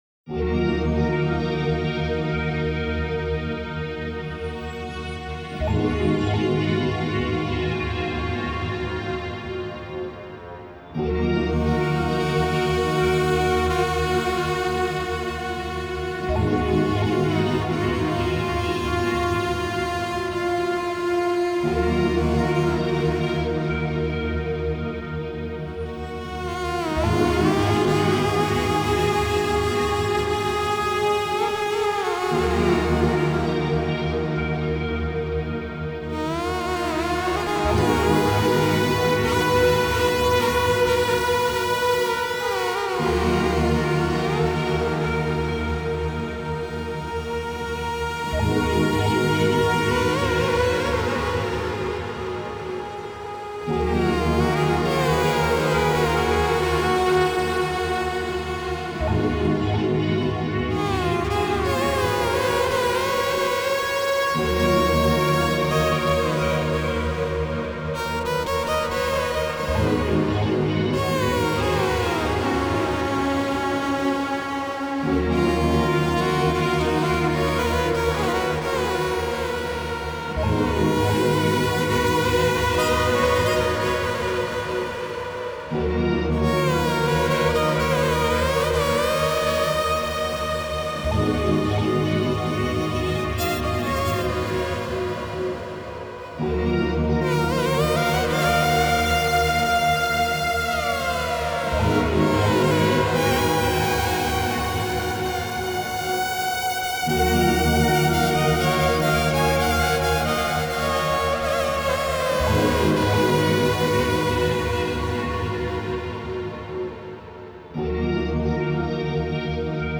Жанр: Electronica, indian classical, Tribal, Drum n Bass